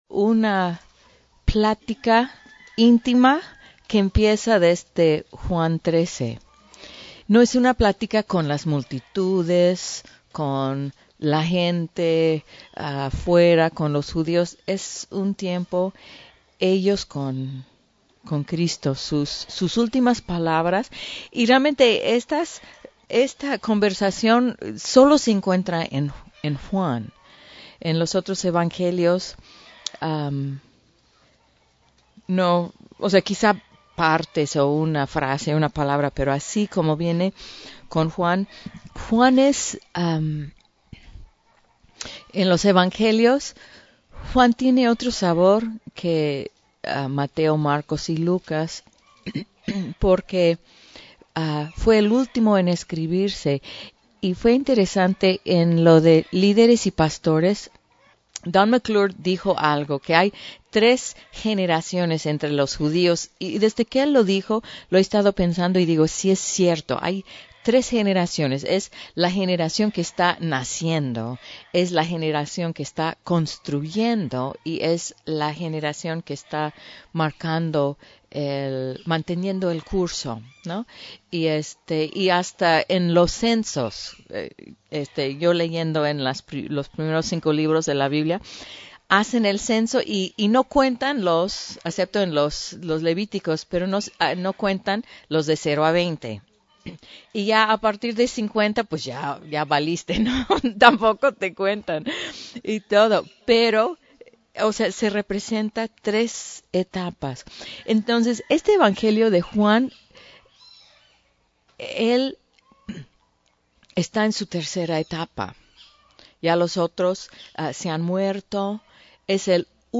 Retiro Staff 2018